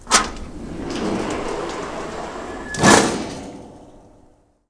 GARAGE 1.WAV